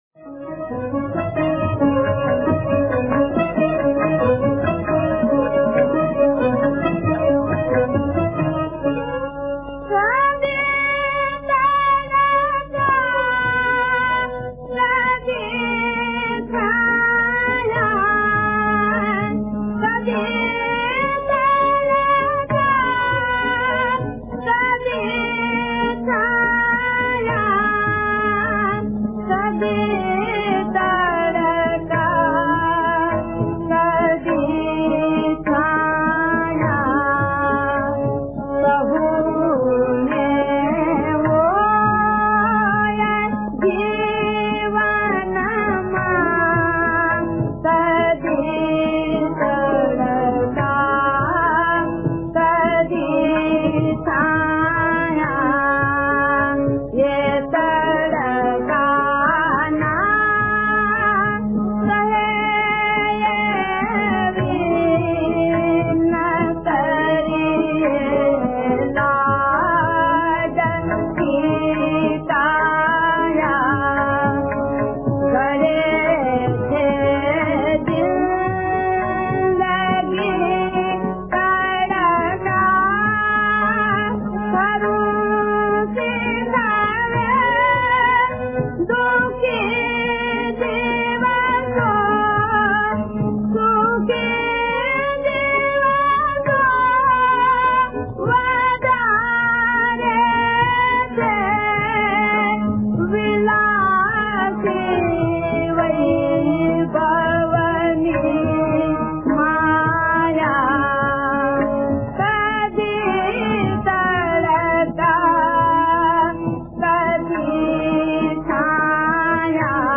ગીત સંગીત લોક ગીત (Lok-Geet)